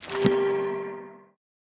Minecraft Version Minecraft Version 25w18a Latest Release | Latest Snapshot 25w18a / assets / minecraft / sounds / block / enchantment_table / enchant1.ogg Compare With Compare With Latest Release | Latest Snapshot